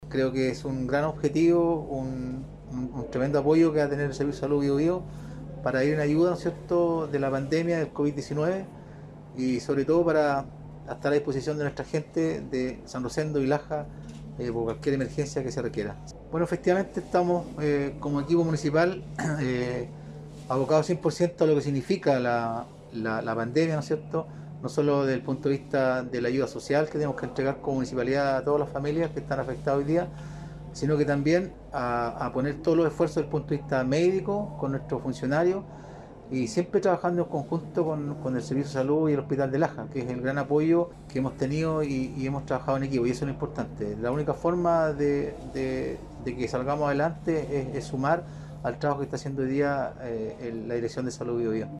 El alcalde de Laja, Vladimir Fica, comentó la importancia de esta medida para combatir la emergencia sanitaria y también se refirió a otras acciones que está desarrollando el equipo municipal.
02-alcalde-laja.mp3